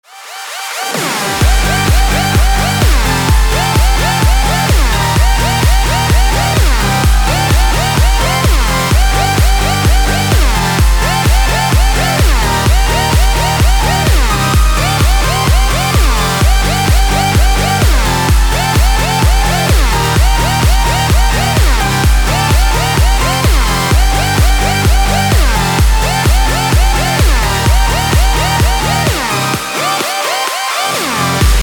• Качество: 256, Stereo
ритмичные
Electronic
без слов
club
house
electro house